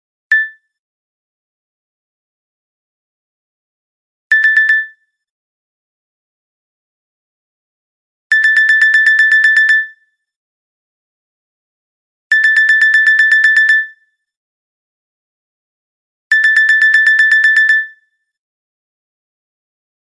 nokia-alert_24721.mp3